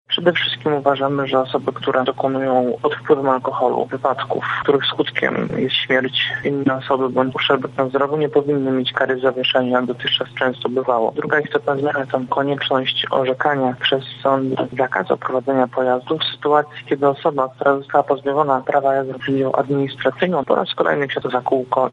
-Projekt jest zainspirowany obecną, złą sytuacją na drogach – mówi rzecznik Ministerstwa Sprawiedliwości Sebastian Kaleta.